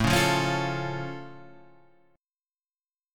A+ chord {5 4 3 6 6 5} chord